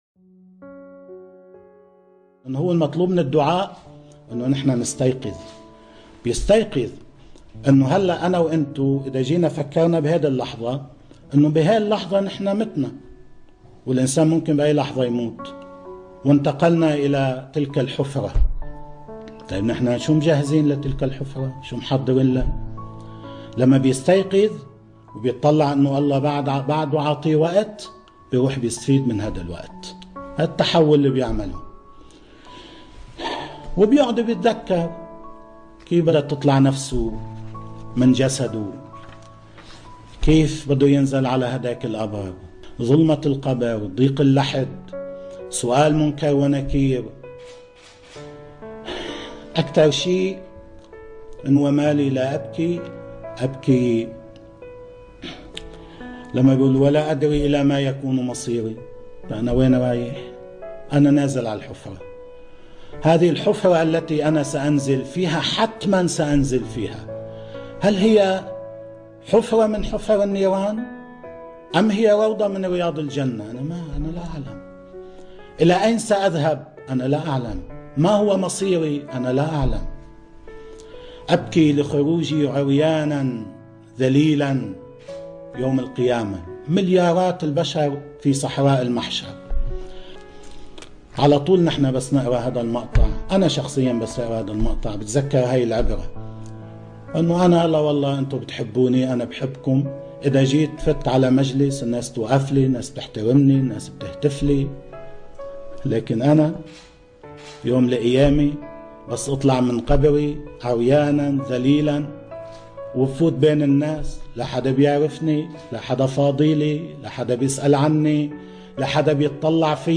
مقطع صوتي وفيديو لكلام سماحة السيد حسن نصر الله عن المطلوب من الدعاء..